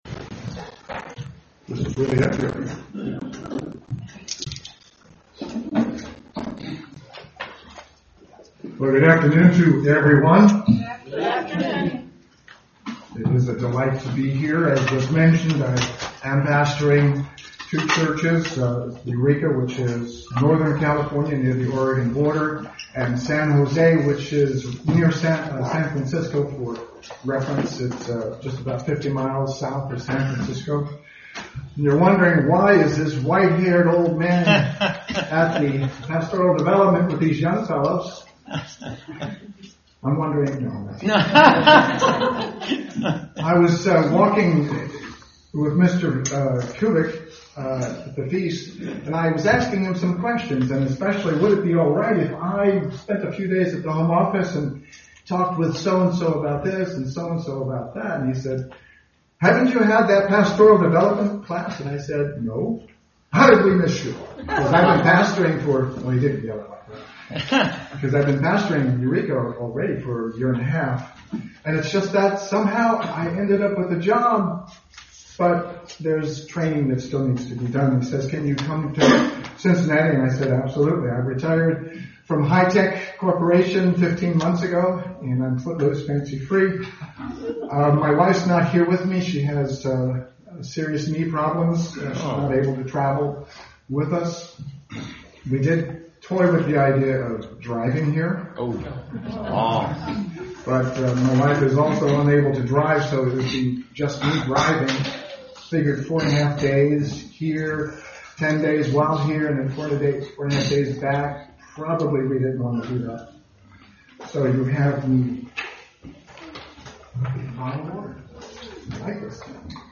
Is it possible for man to love another without ever seeing or meeting them? In this sermon discover how it is not only possible, it is a necessity!